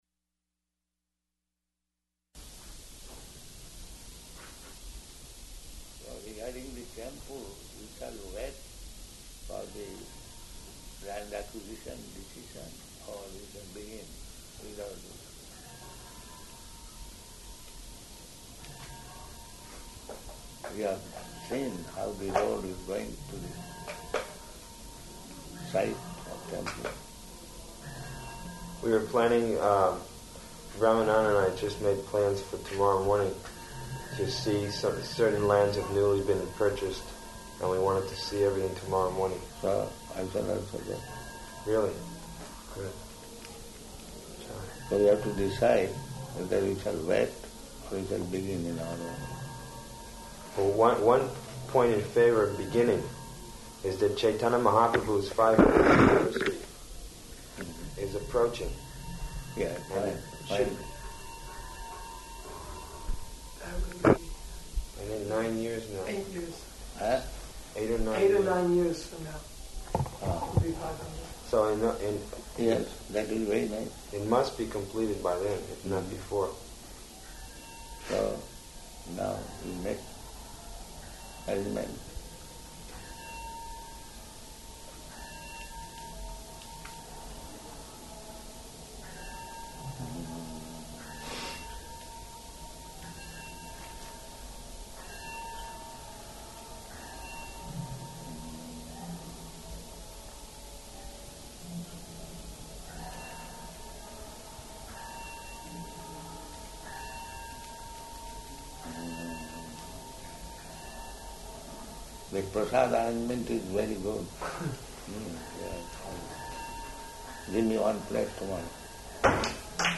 Type: Conversation
Location: Māyāpur